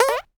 cartoon_boing_jump_13.wav